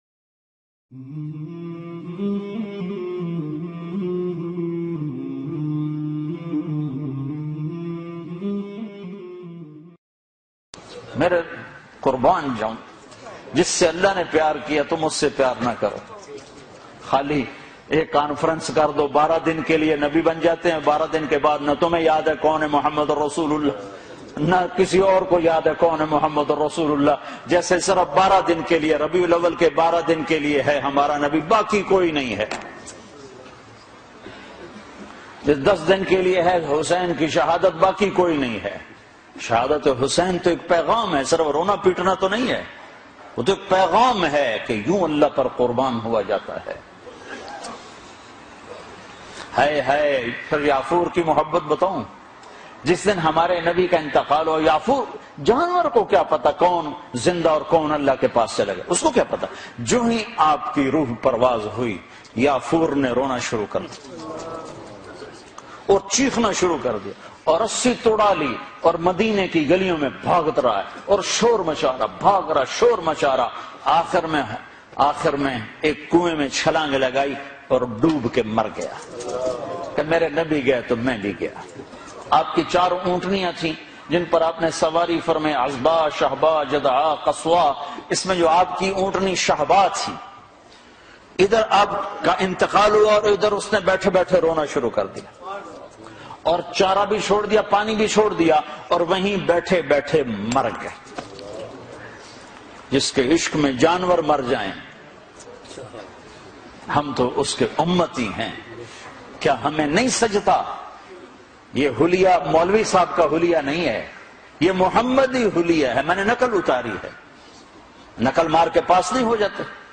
Eid Milad Un Nabi Manana Kaisa Hai Beautifull Bayan MP3